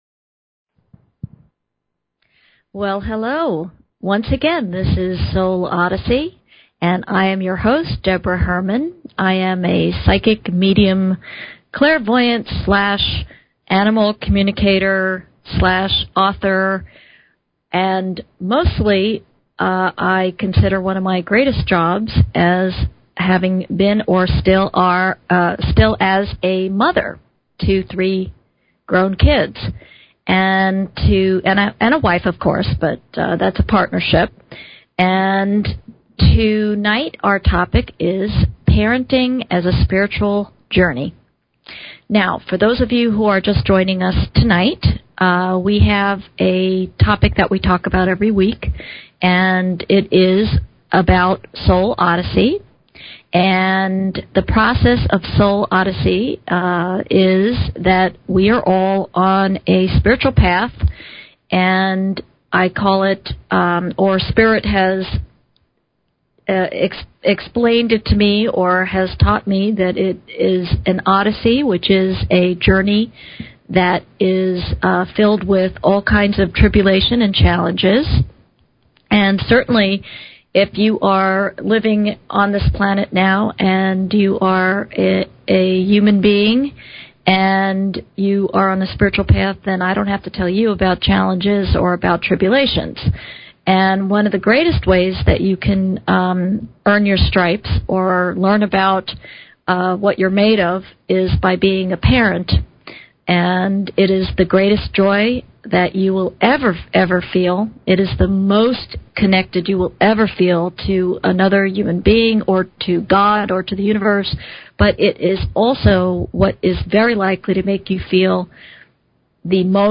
Talk Show Episode, Audio Podcast, Soul_Odyssey and Courtesy of BBS Radio on , show guests , about , categorized as